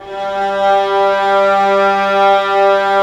Index of /90_sSampleCDs/Roland LCDP13 String Sections/STR_Violas FX/STR_Vas Sordino